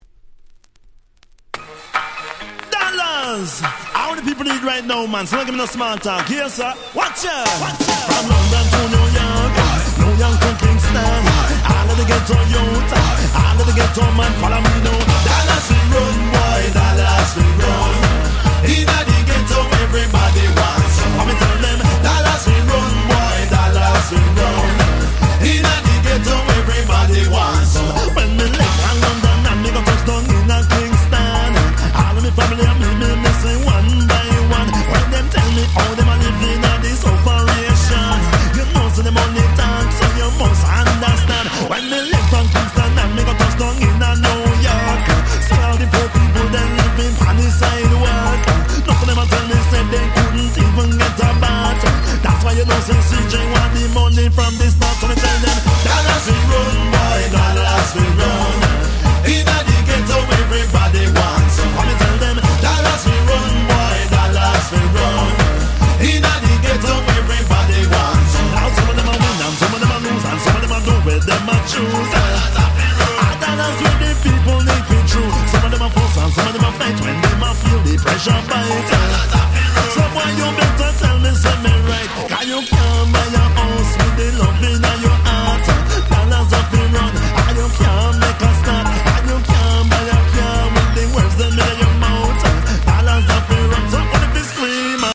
94' Smash Hit Reggae / Ragga Pop !!
軽快なDanceトラックで、上記のアルバムの中でも一番ちゃんと''レゲエ''していた1曲です(笑)